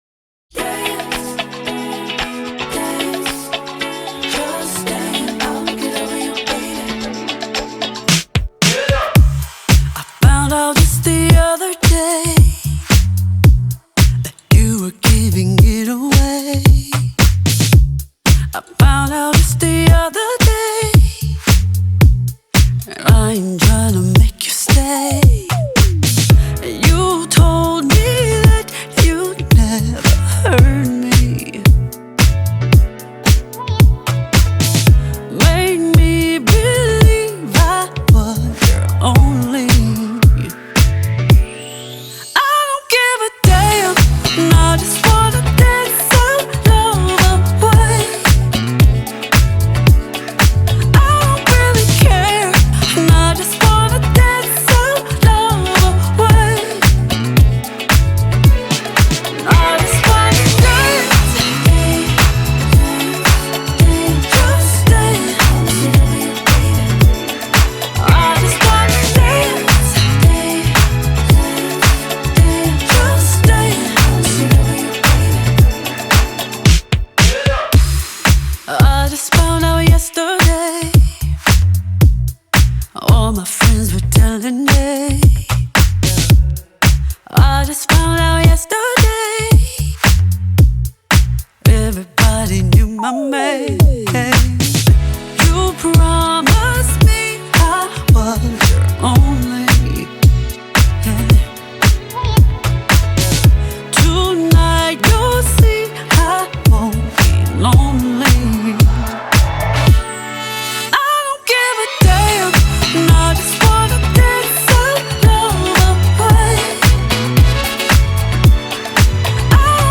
R&B legend